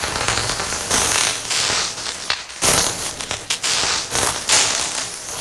elec_lightning_magic_arc_loop2.wav